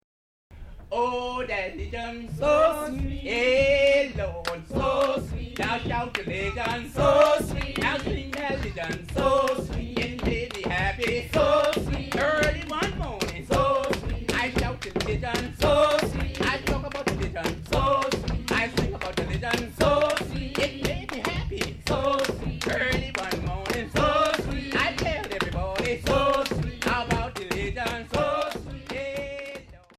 McIntosh County Shouters: Slave Shout Songs from the Coast of Georgia
usually precedes the performance of this shout with a skit he does with the Shouters in which he assumes the high voice of a woman assembling her children to sing this song for her birthday.
The recordings included here made over a period of two years in McIntosh and Glynn Counties in Georgia. Some performances were recorded at the 1983 Sea Island Festival on St. Simon's; other songs were recorded in two recording sessions in the annex of Mt. Calvary Baptist Church.